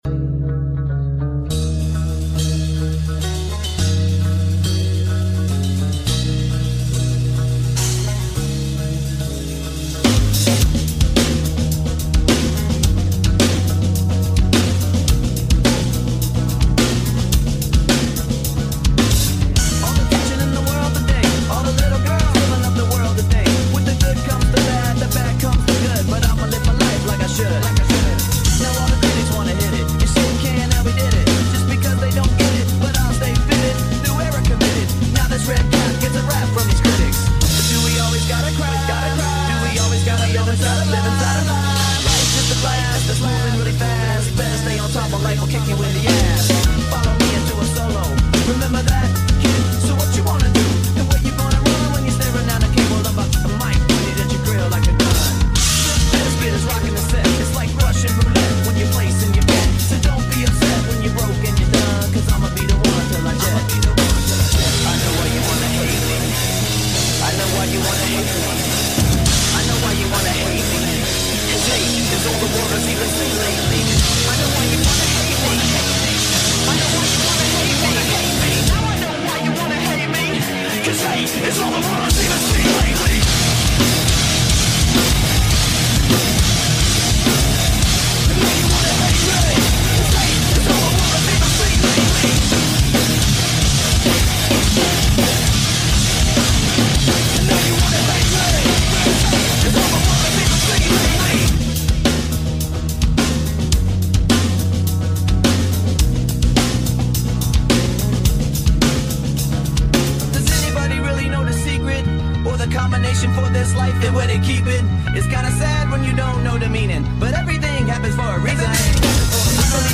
เลื่อยยนต์TOMITSUรุ่นTMS9500 ลูกสูบ2แหวน ตัดเอียงได้360*ร้อนไม่ดับ พลาสติกด้าน เกรดดี